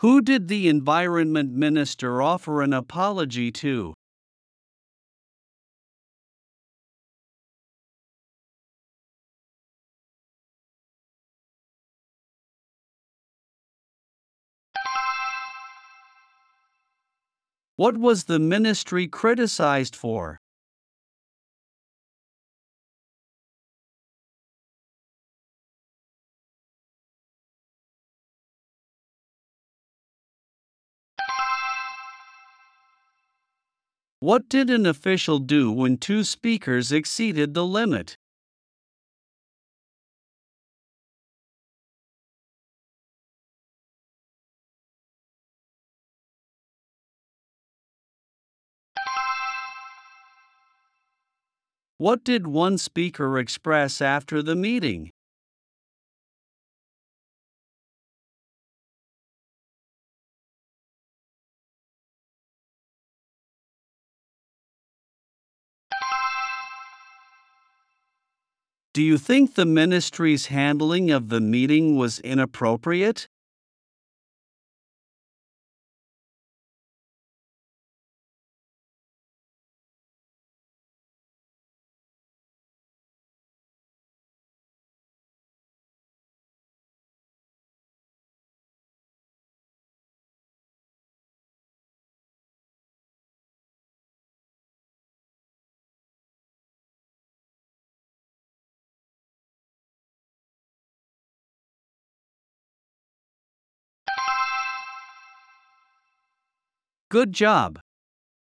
プレイヤーを再生すると英語で5つの質問が1問ずつ流れ、10秒のポーズ（無音部分）があります。
10秒後に流れる電子音が終了の合図です。
Only Q5 in the B2 level version allows for a 30-second response time.